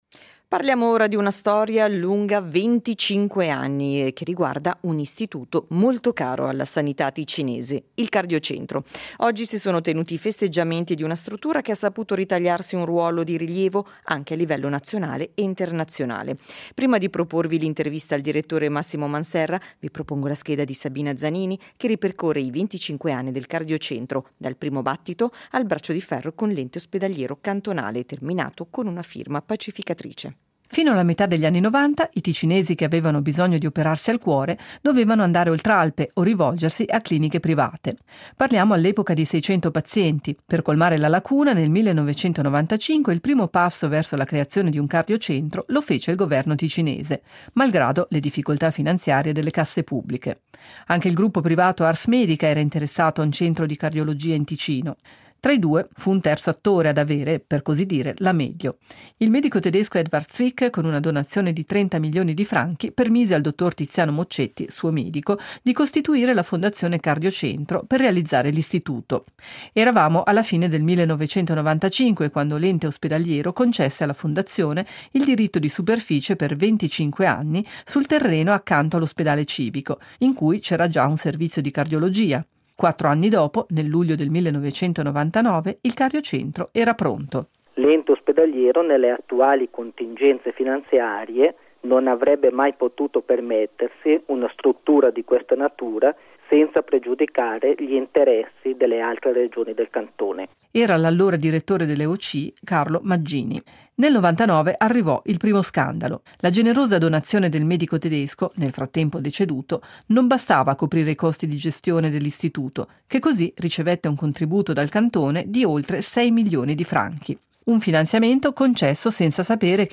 SEIDISERA 21.09.2024 – Intervista